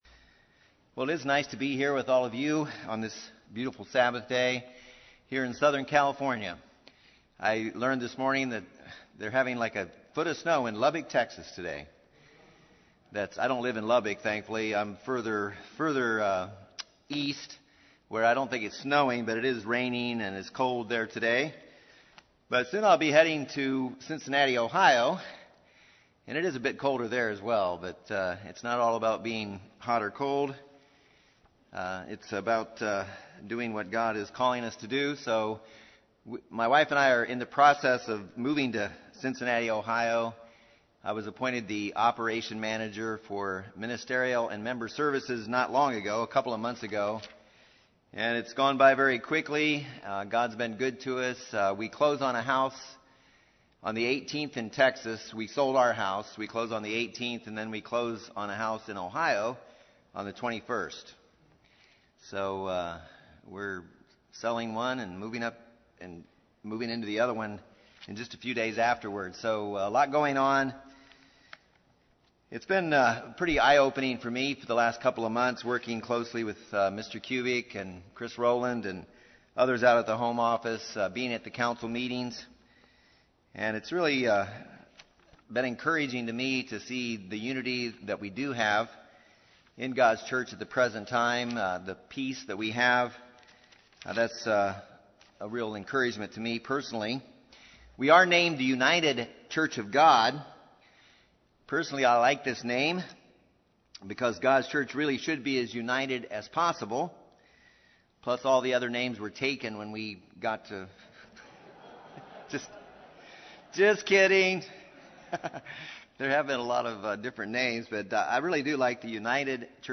This message reflects on the qualities of unity, integrity and humility, and posits that true integrity plus true humility result in true unity.